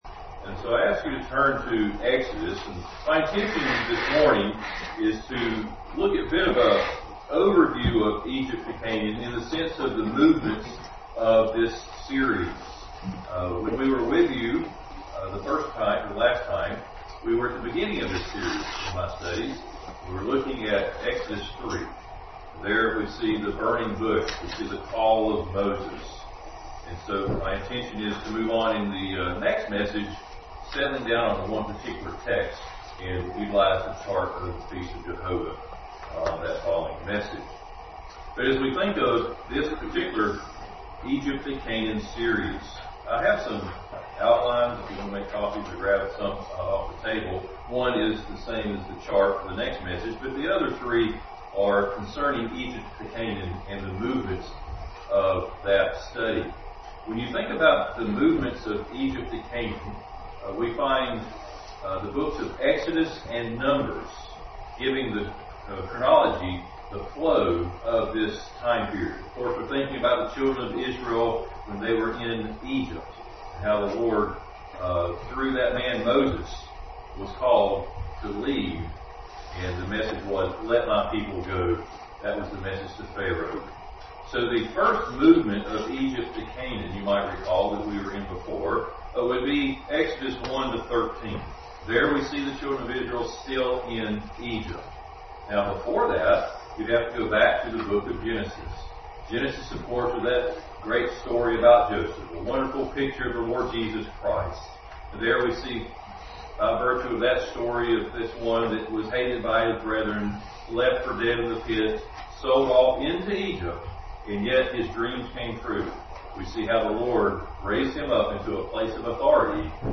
Egypt to Canaan Passage: Exodus 3, 18-34, 1 Corinthians 10:1-13, Numbers 10:11-15 Service Type: Sunday School